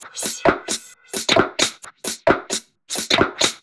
Index of /musicradar/uk-garage-samples/132bpm Lines n Loops/Beats
GA_BeatDSweepz132-05.wav